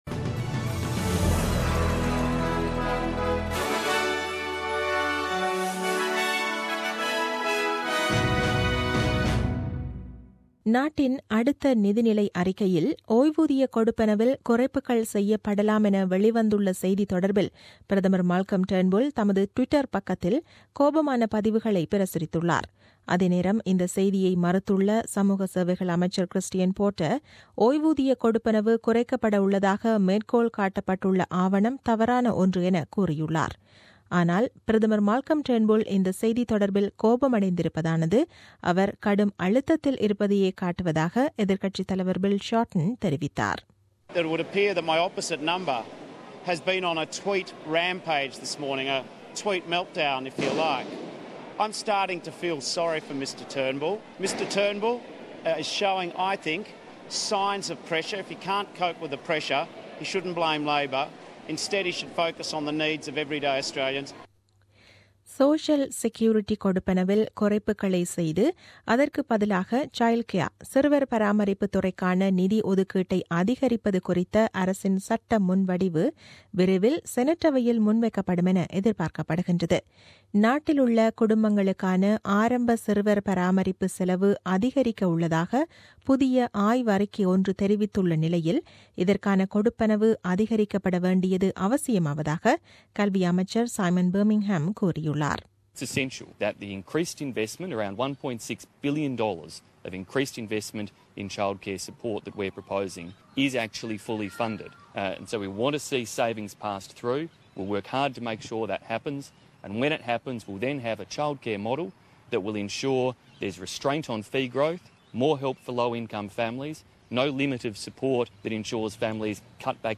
Australian news bulletin aired on Sunday 19 Mar 2017 at 8pm.